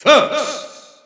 Announcer pronouncing Fox in French.
Fox_French_Announcer_SSBU.wav